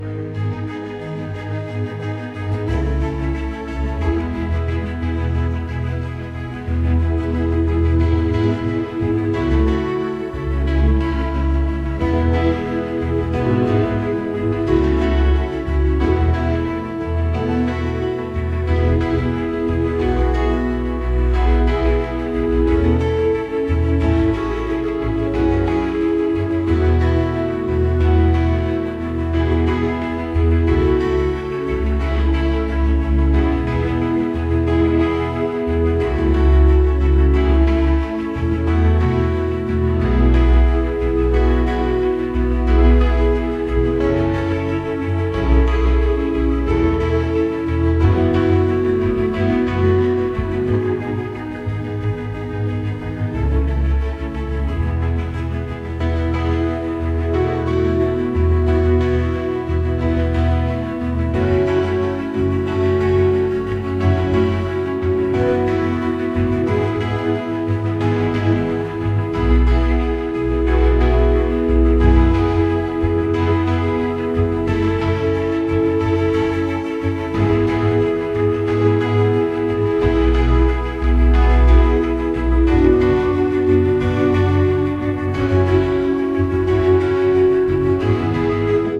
「不気味」